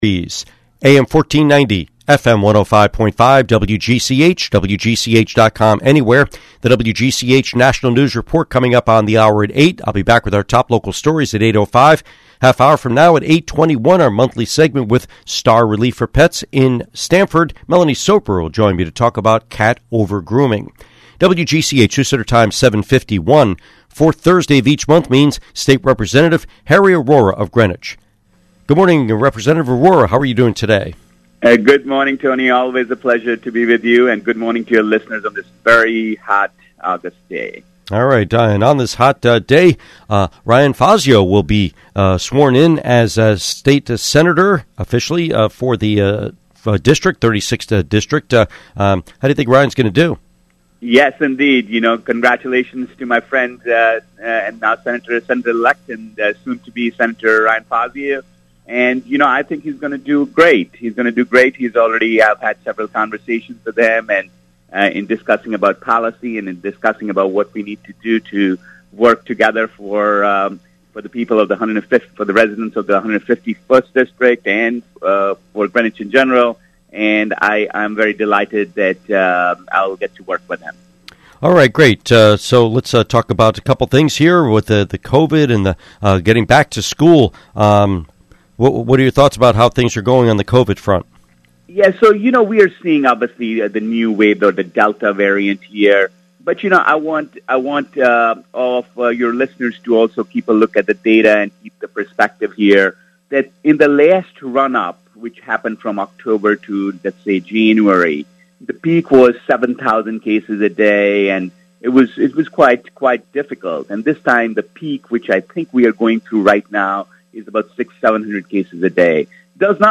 Interview with State Representative Harry Arora